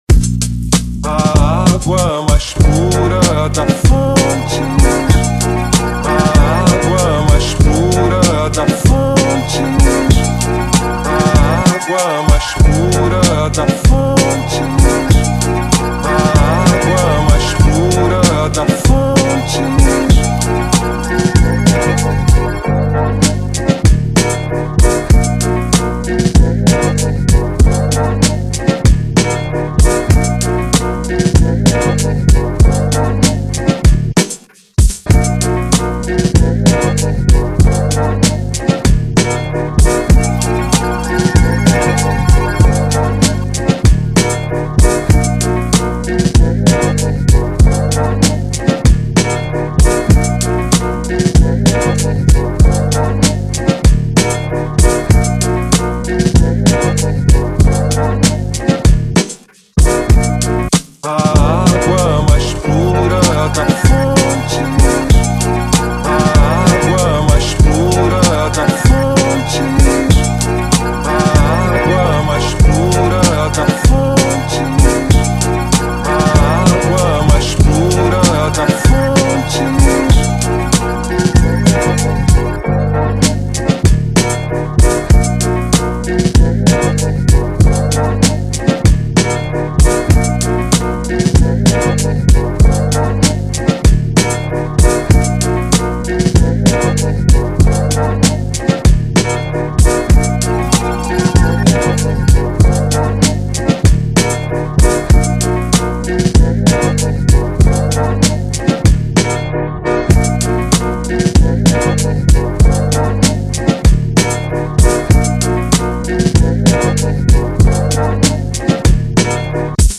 Boom Bap Instrumental